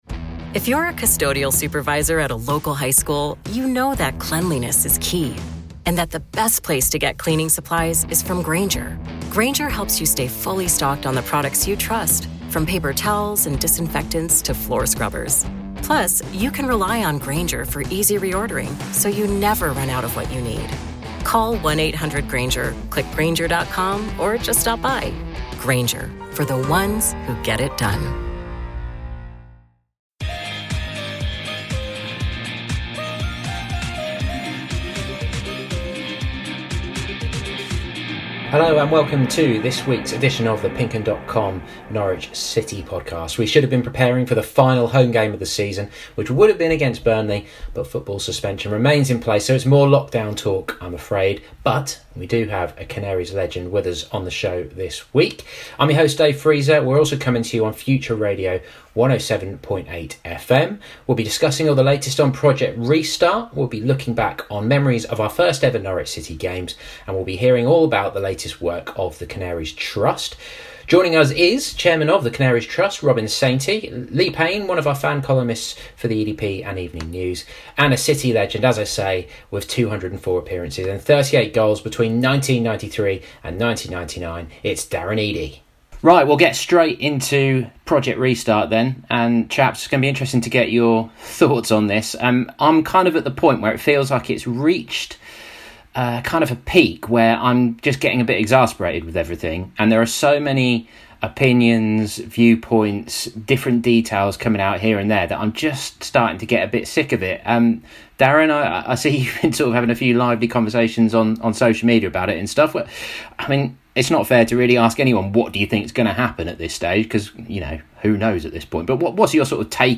was joined on a video call